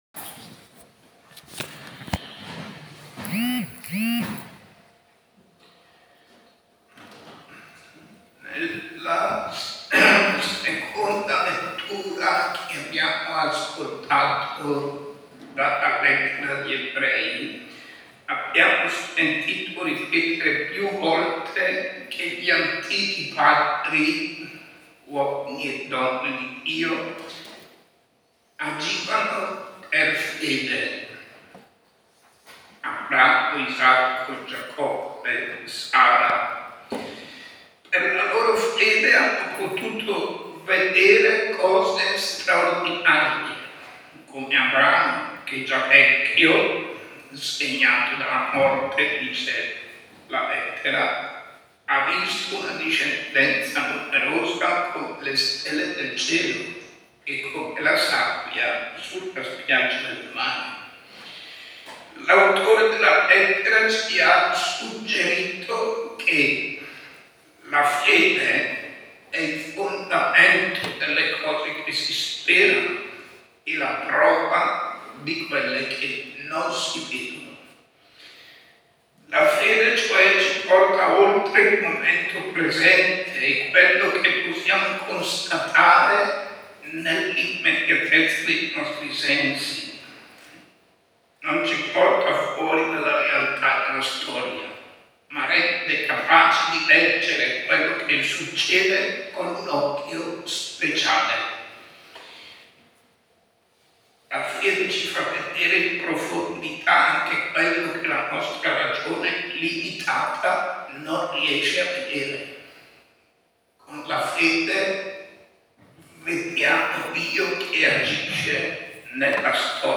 Omelia-del-7-agosto-2022.mp3